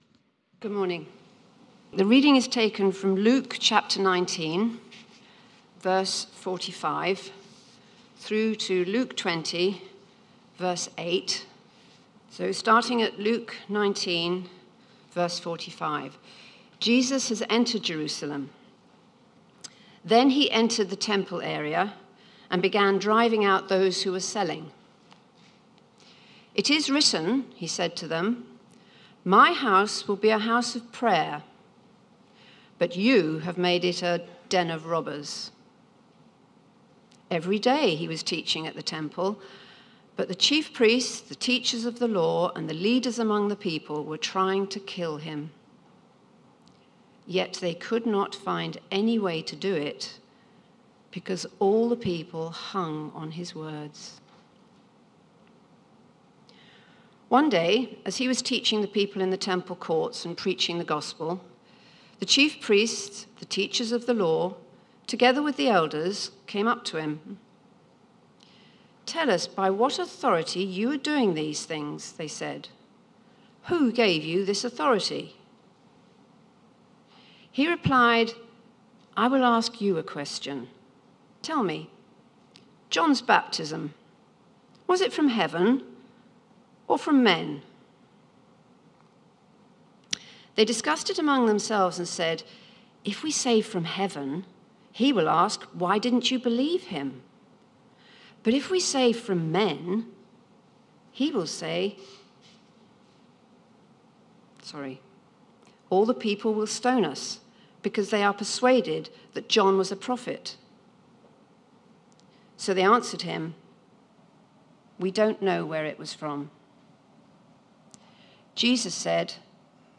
Media for Sunday Service on Sun 22nd Jan 2023 10:00
Passage: Luke 19:45-20:8 Series: Book of Luke Theme: Sermon